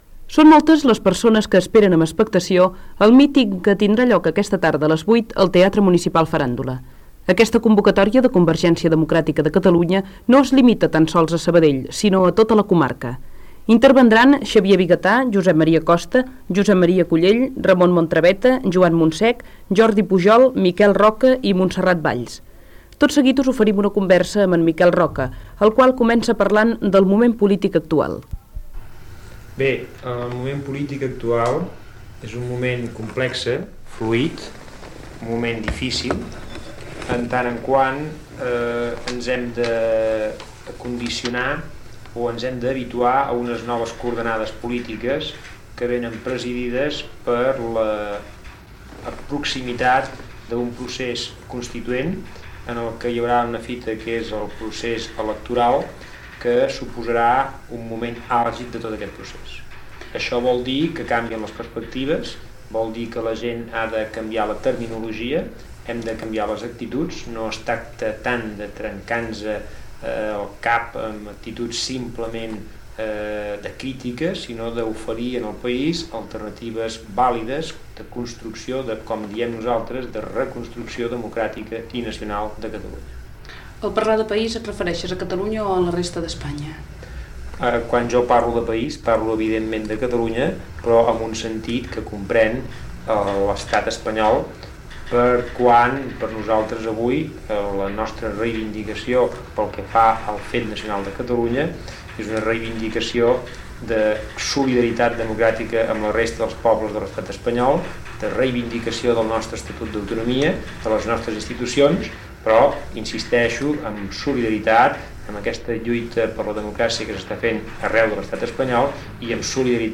Entrevista a Miquel Roca Junyent sobre el míting d'aquell dia de Convergència Democràtica de Catalunya a Sabadell
Informatiu